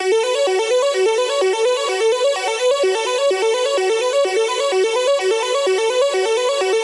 简单的Arp 140 BPM
描述：使用Nord 2X和第三方效果创建的简单arp。
Tag: 140-BPM 电子 环路 音乐 样品 EDM 恍惚间 舞蹈 阿普